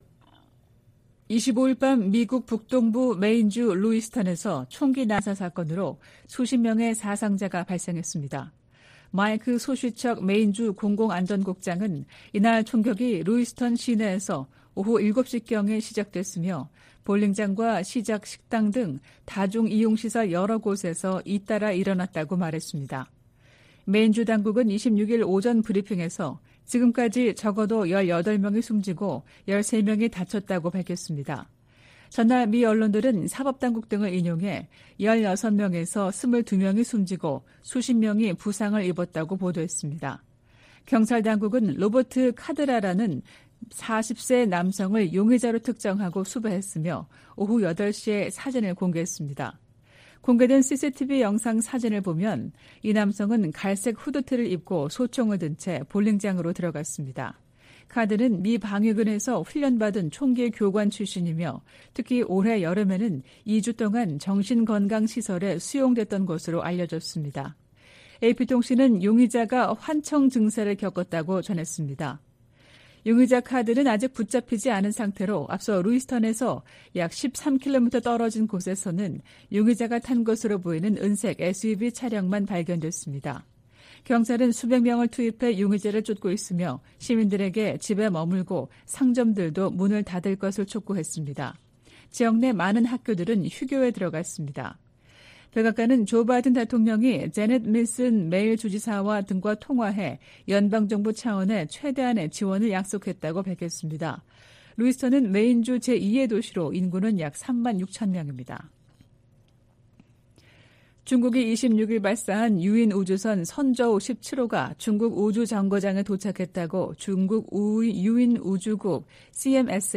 VOA 한국어 '출발 뉴스 쇼', 2023년 10월 27일 방송입니다. 미국, 한국, 일본 세 나라 외교장관들이 북한과 러시아 간 불법 무기 거래를 규탄하는 공동성명을 발표했습니다. 북한은 유엔에서 정당한 우주 개발 권리를 주장하며 사실상 우주발사체 발사 시도를 계속하겠다는 뜻을 내비쳤습니다.